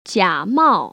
[jiămào] 지아마오